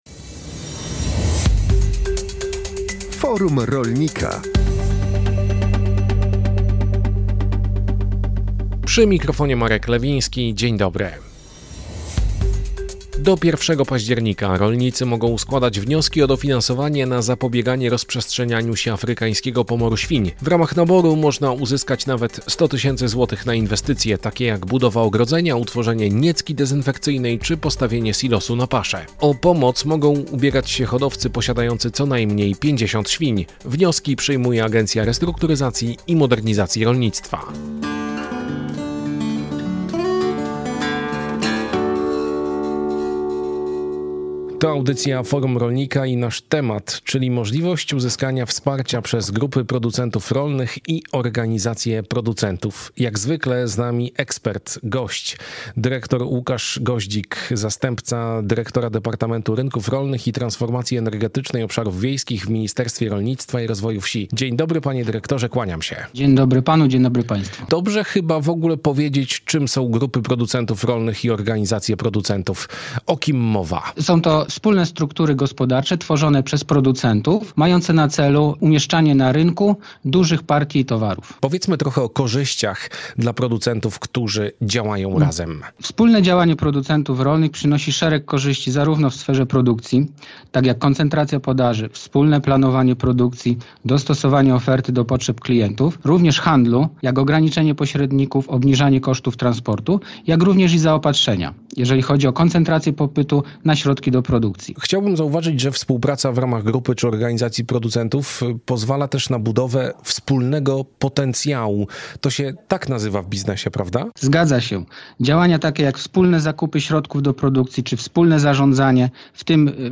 Audycja o tematyce rolnej „Forum Rolnika” emitowana jest na antenie Radia Kielce w środy po godz. 12.